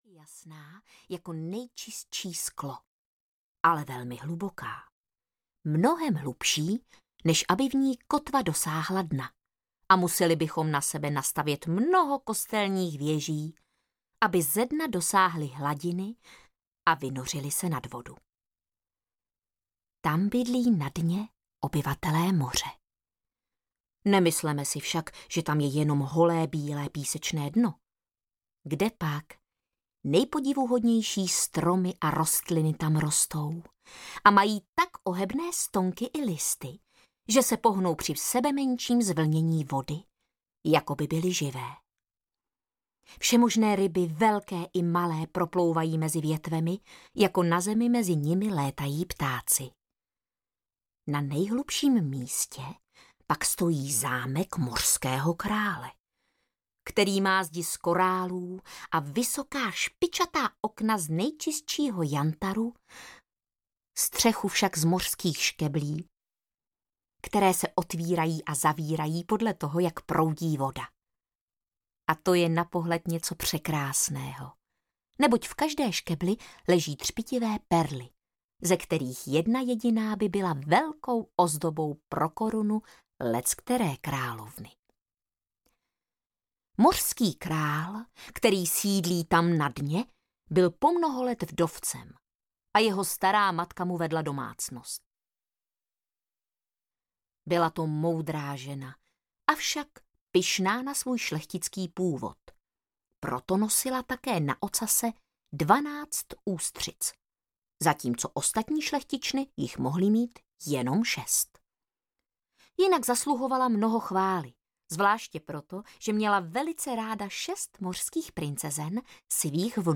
Pohádky o lásce audiokniha
Ukázka z knihy
• InterpretVáclav Knop